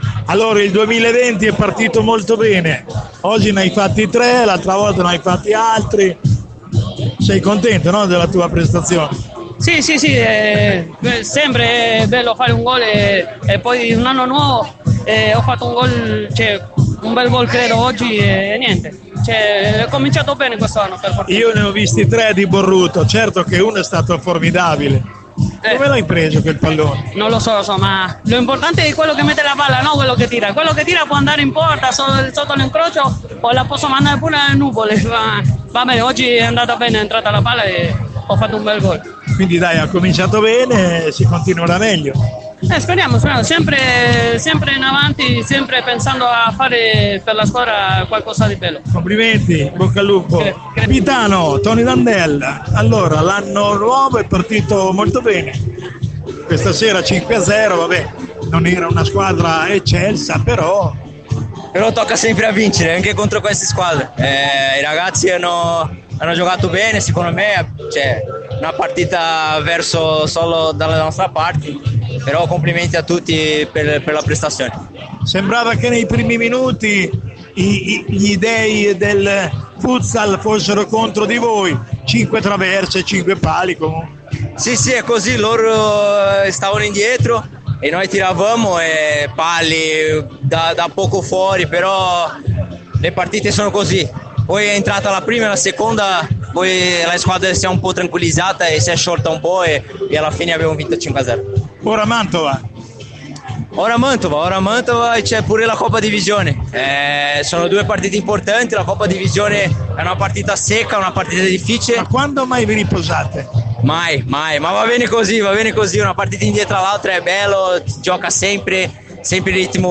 Interviste Post Gara Italservice Vs CDM Genova